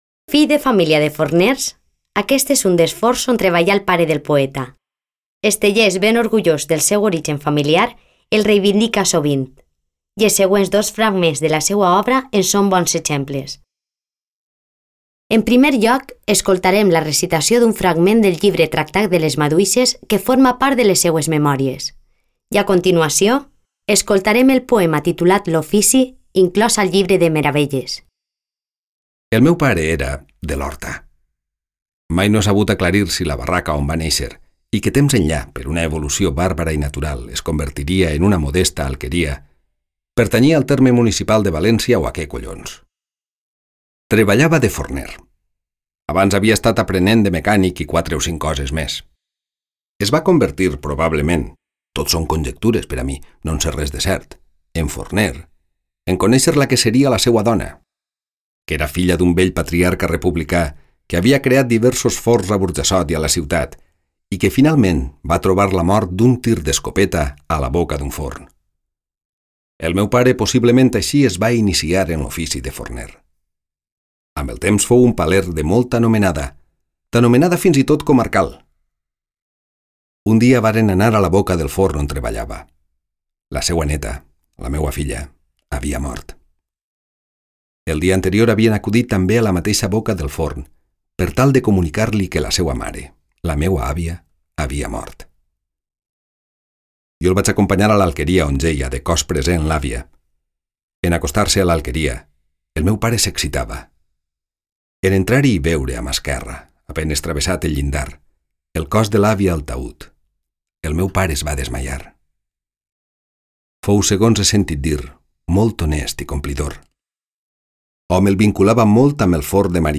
Explicació en àudio:
En primer lloc, escoltarem la recitació d’un fragment del llibre Tractat de les maduixes, que forma part de les seues memòries.